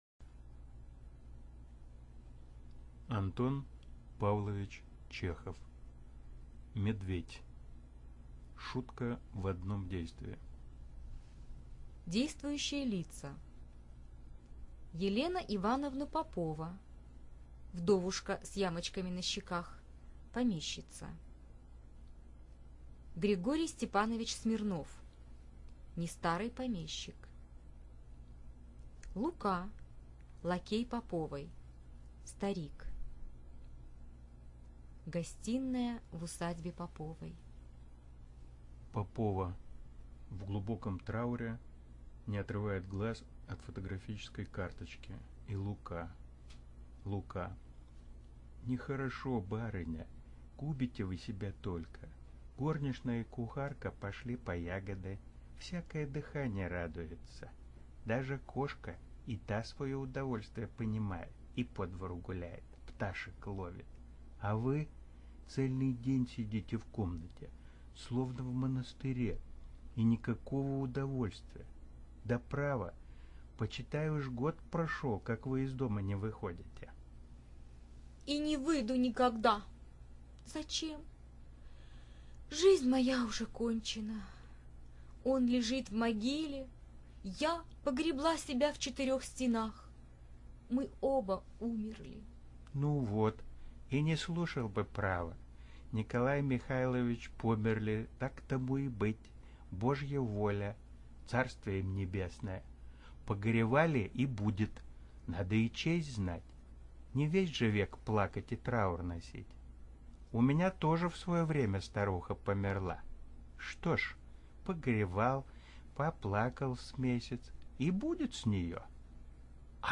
Audio play: The Boor by Anton Chekhov
Аудиопьеса: Медведь. А. П. Чехов